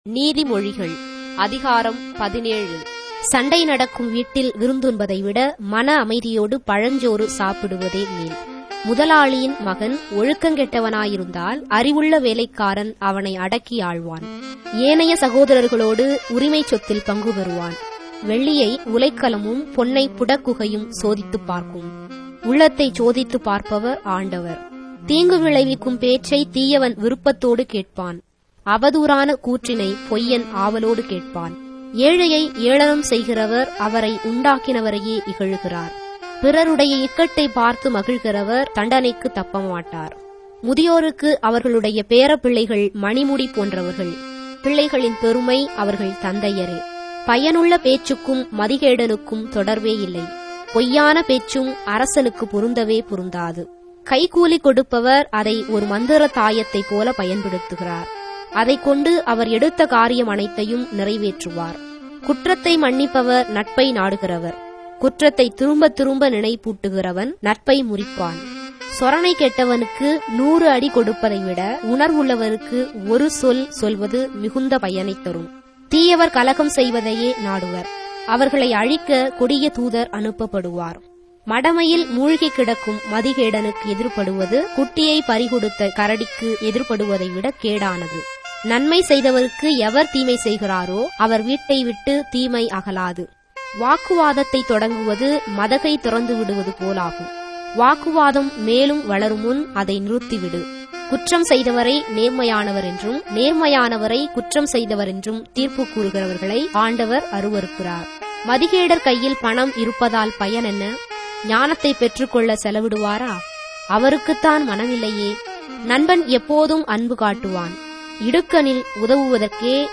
Tamil Audio Bible - Proverbs 7 in Ecta bible version